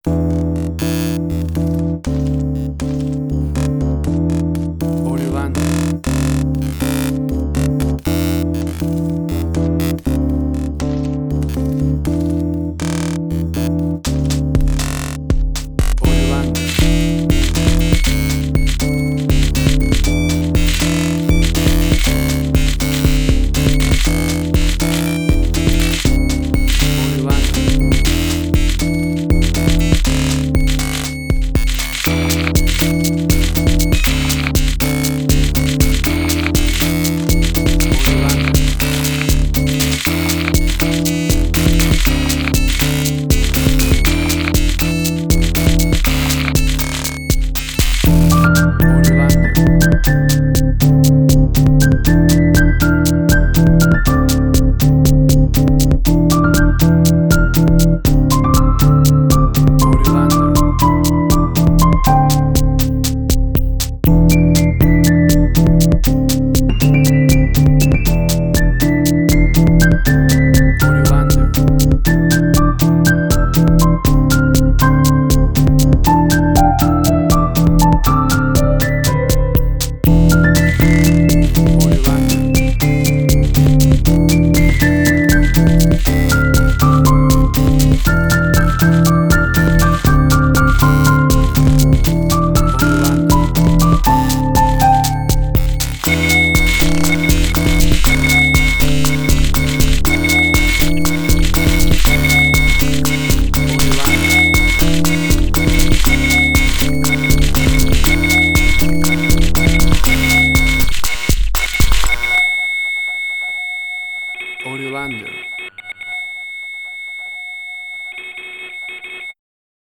IDM, Glitch.
WAV Sample Rate: 16-Bit stereo, 44.1 kHz
Tempo (BPM): 120